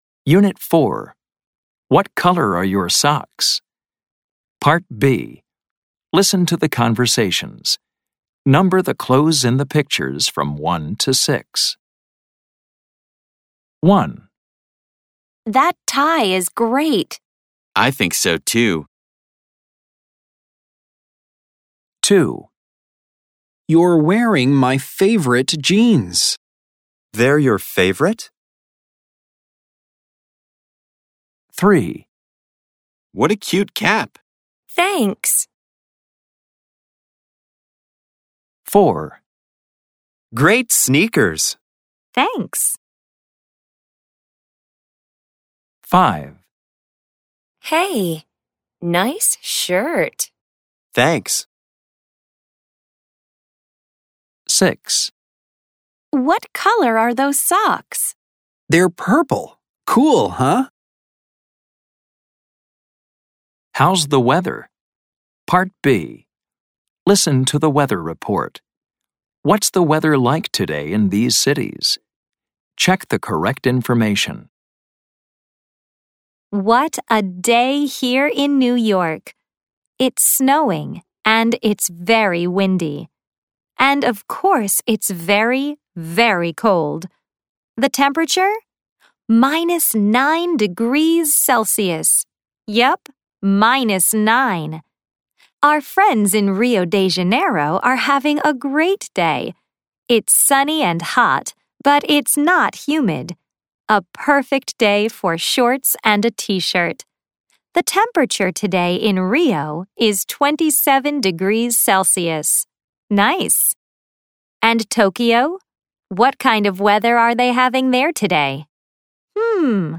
American English
This includes Snapshots, Conversations, Grammar Focus, Listening, Pronunciation practice, Word Power, and Reading, all recorded in natural conversational English.